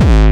techno flavour kick.wav